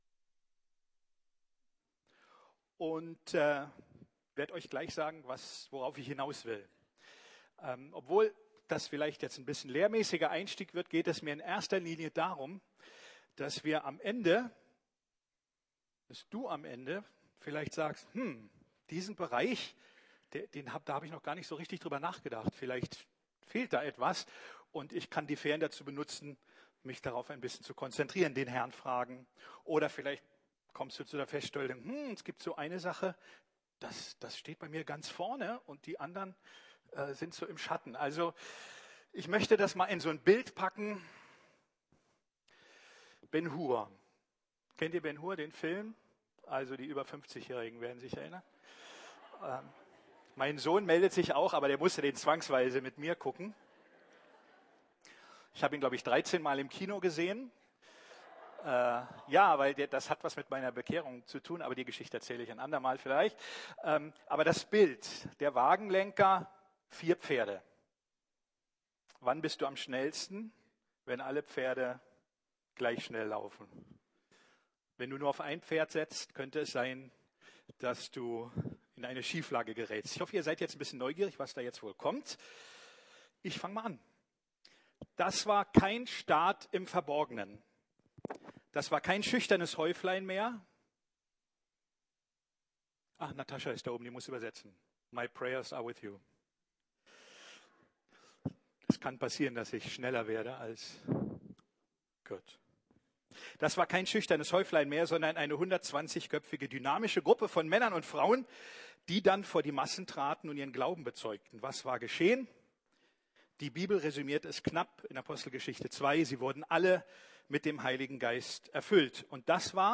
Vier Dimensionen des Christseins ~ Predigten der LUKAS GEMEINDE Podcast